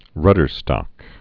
(rŭdər-stŏk)